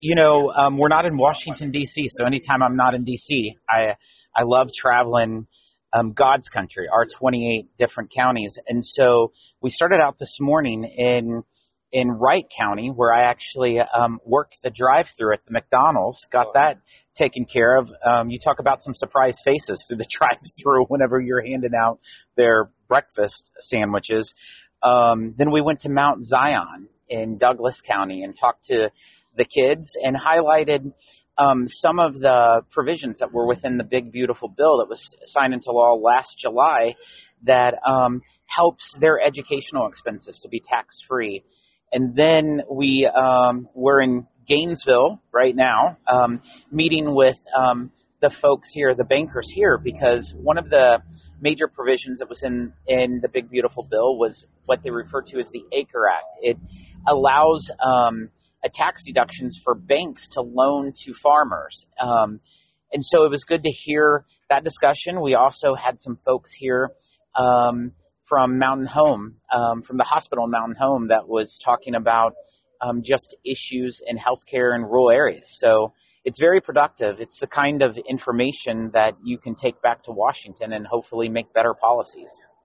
We spoke with Smith after the meeting: